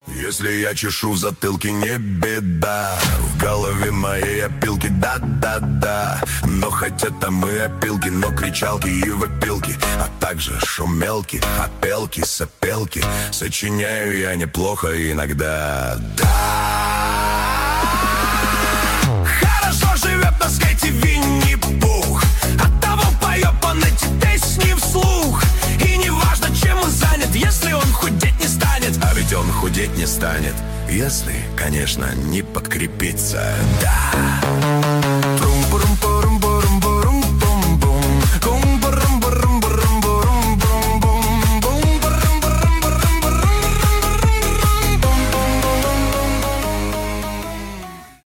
веселые , прикольные , русские
танцевальные , cover , нейросеть